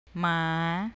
MAAH DOG